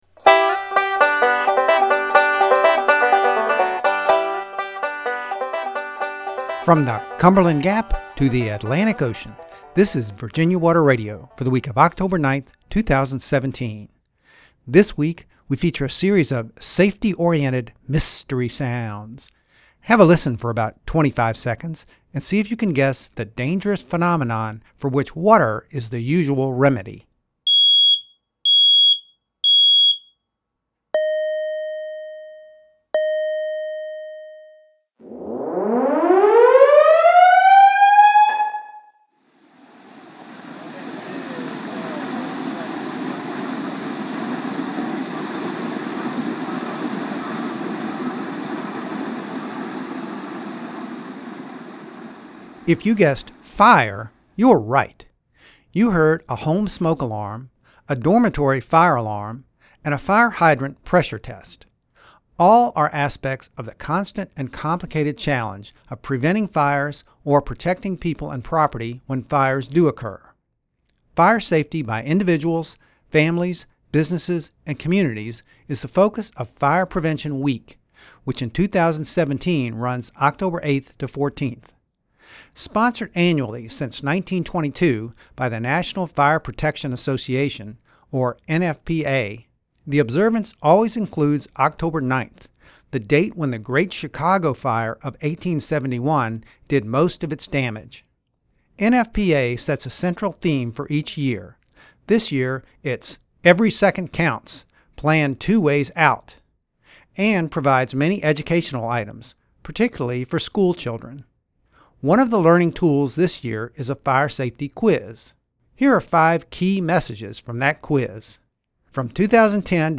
The hydrant pressure-test sound was recorded on the Virginia Tech campus in Blacksburg on March 10, 2017.